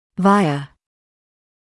[‘vaɪə][‘вайэ]посредством, с помощью; через